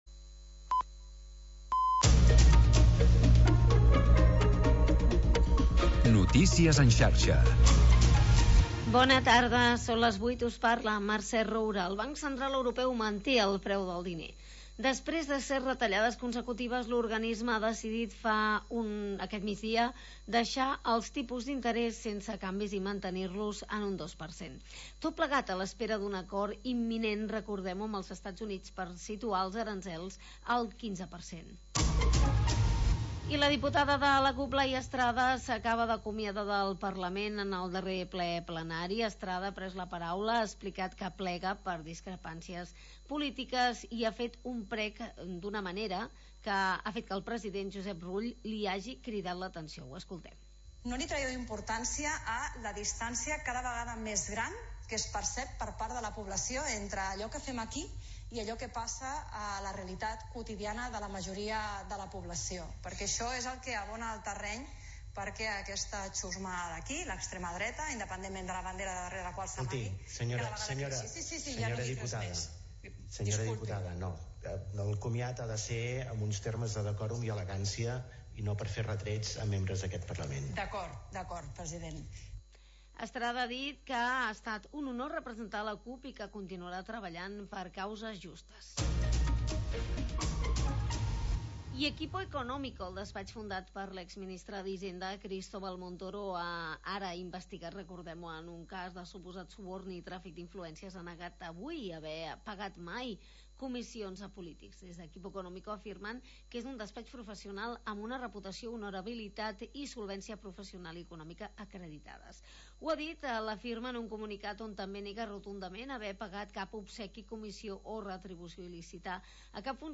Espai per la música independent